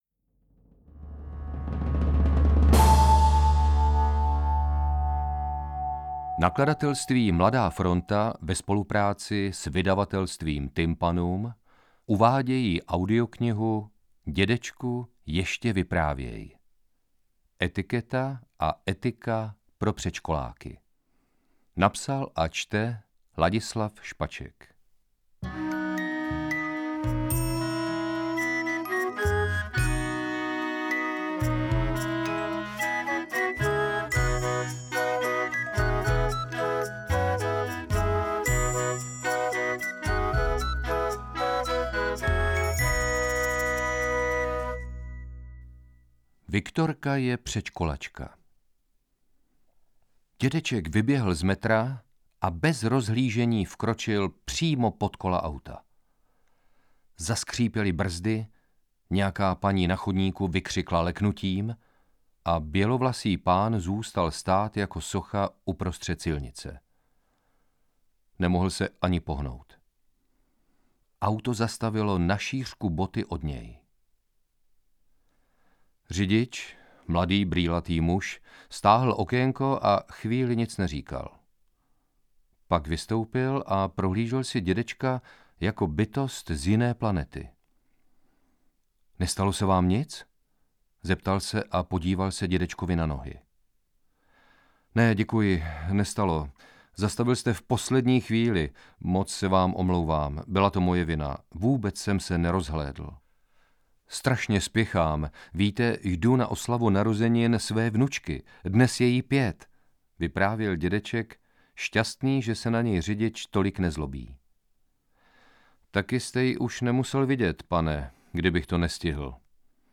Interpret:  Ladislav Špaček
AudioKniha ke stažení, 11 x mp3, délka 1 hod. 39 min., velikost 230,3 MB, česky